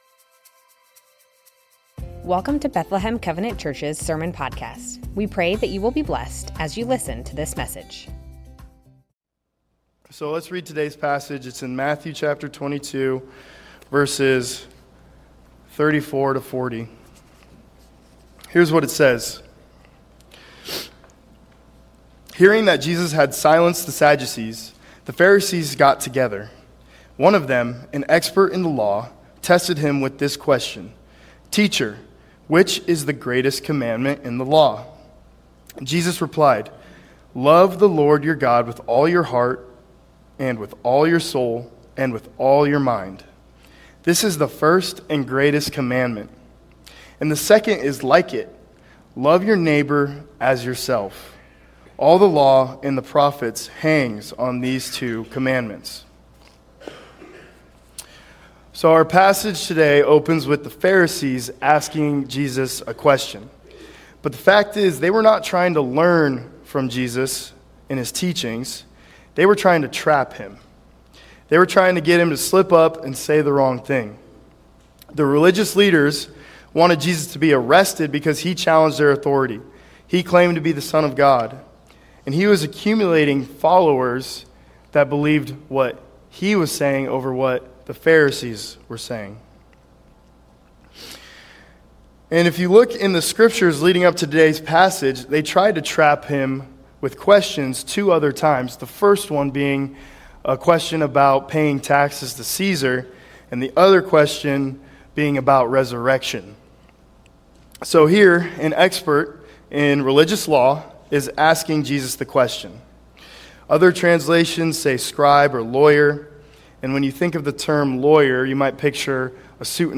Bethlehem Covenant Church Sermons Matthew 22:34-40 - The Greatest Jul 13 2025 | 00:20:09 1x 00:00 / 00:20:09 Subscribe Share Spotify RSS Feed Share Link Embed